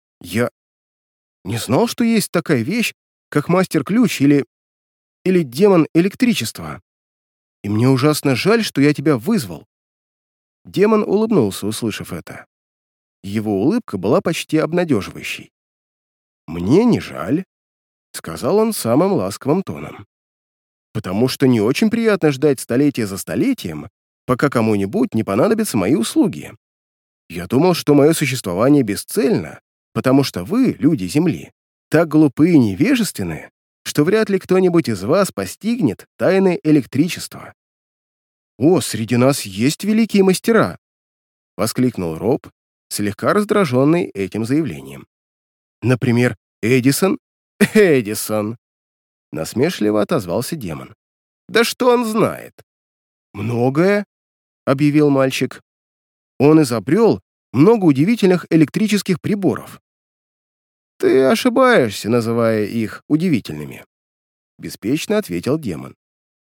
Тракт: Микрофон Rode K-2, предусилитель DBX 376